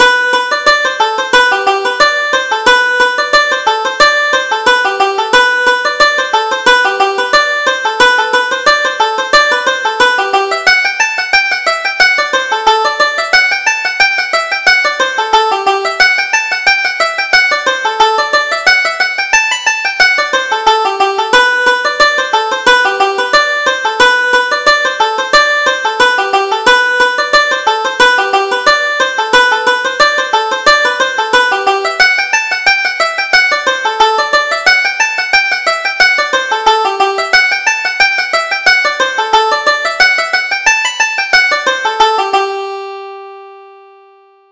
Reel: Nellie O'Donovan